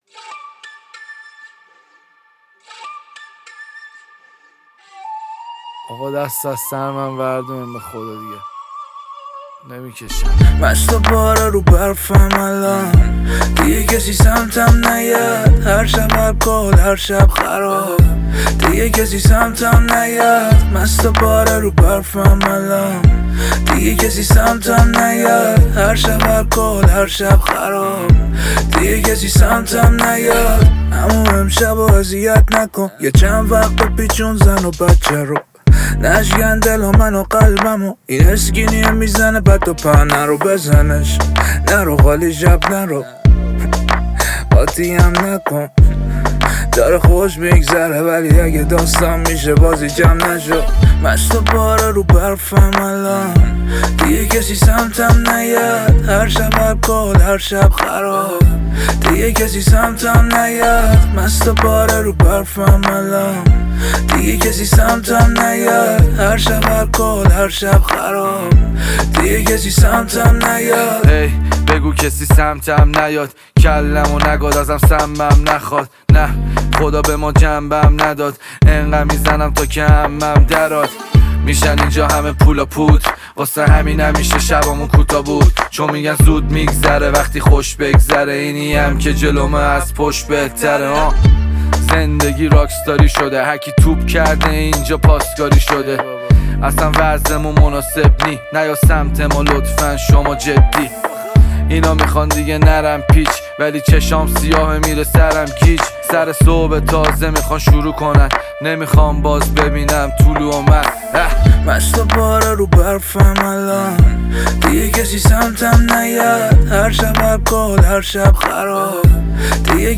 موزیک،رپ